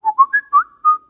Samsung Whistle Sound Effect Free Download
Samsung Whistle